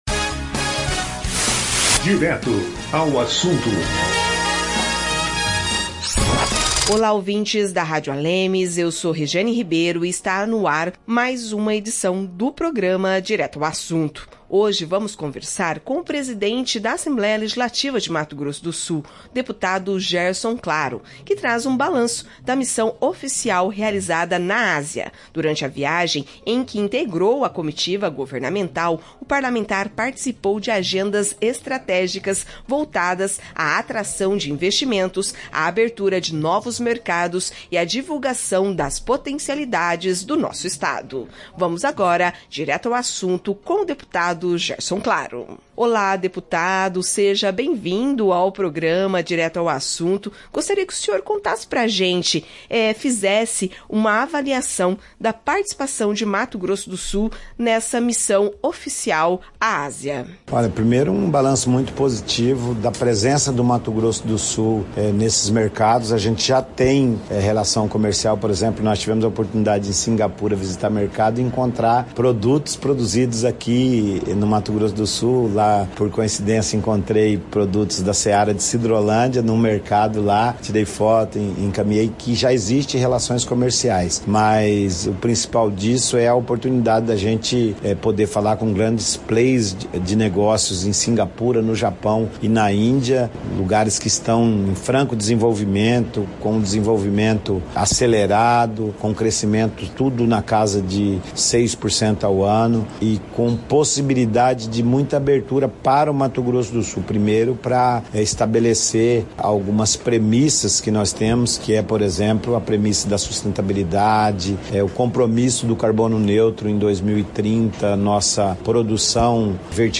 Em entrevista ao programa Direto ao Assunto, da Rádio ALEMS, o presidente da Assembleia Legislativa, deputado Gerson Claro (PP), falou sobre a agenda internacional realizada em países asiáticos e ressaltou as oportunidades de novos investimentos e a visibilidade que Mato Grosso do Sul conquistou no exterior.